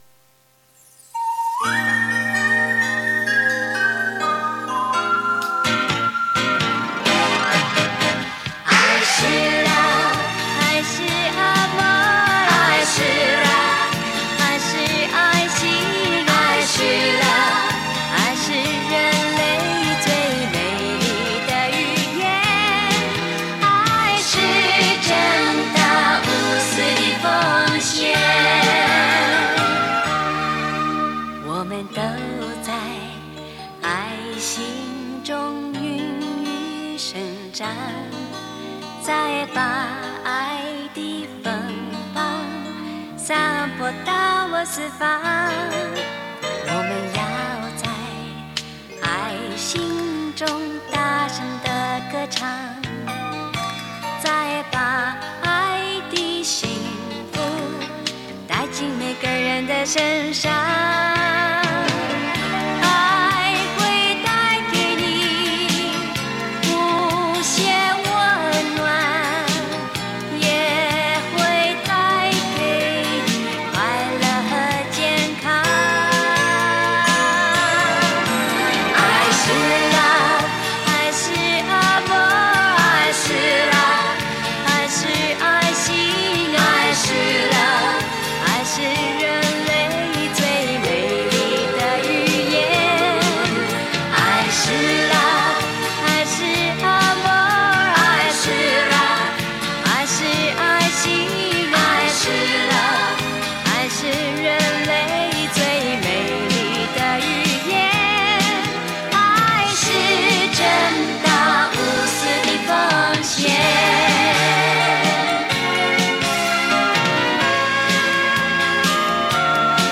律动流畅